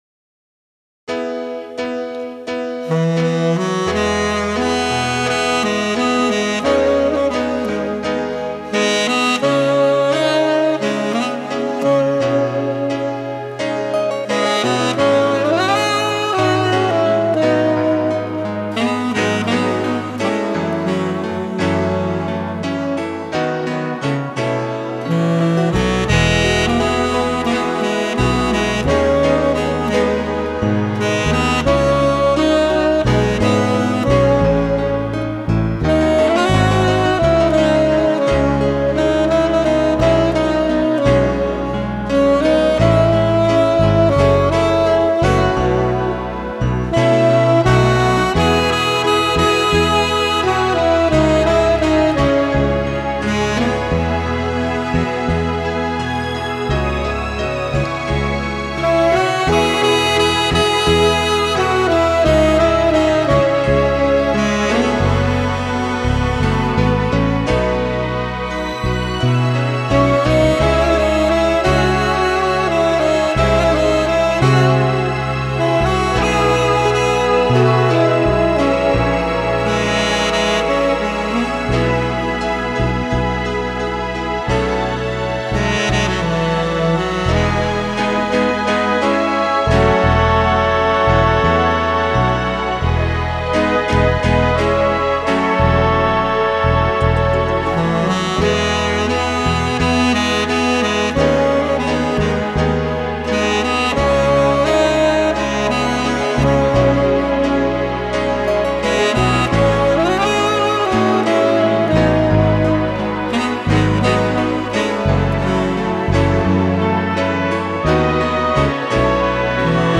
• Категория:Мелодии на саксофоне